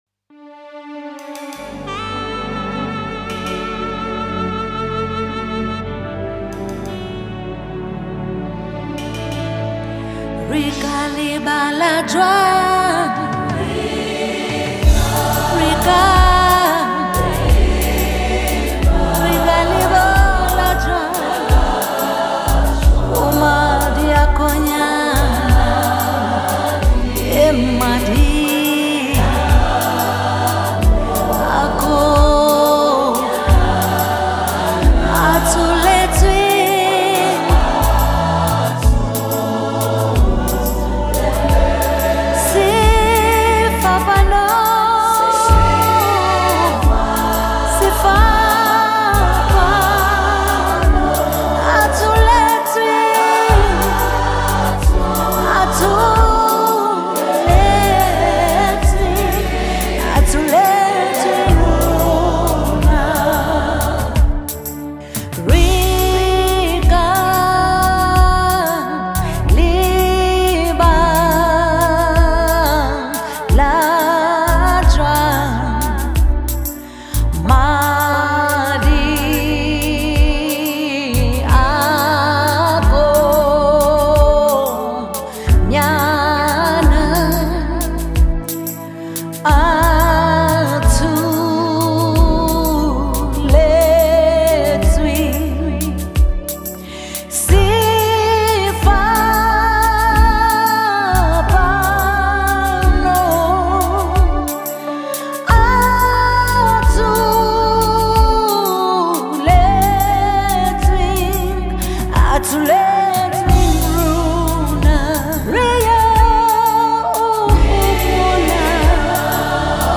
January 30, 2025 Publisher 01 Gospel 0
was a South African gospel singer.